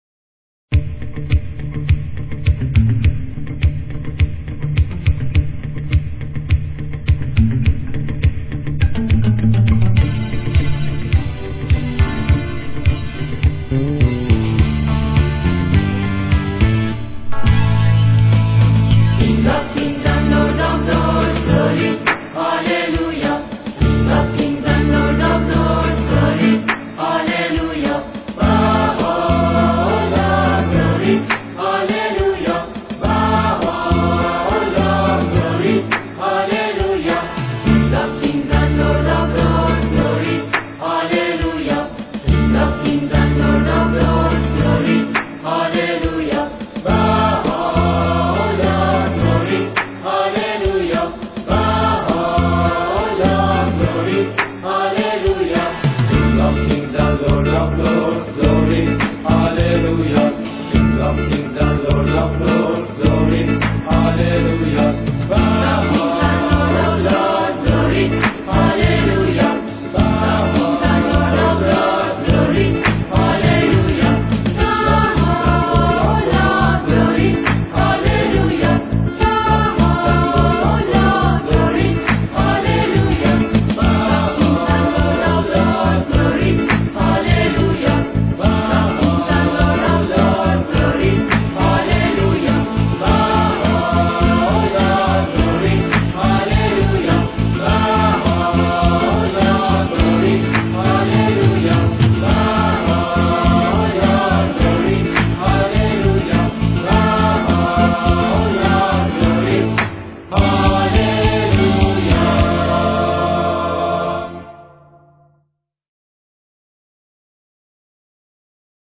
مجموعه سرود و مناجات به زبان انگلیسی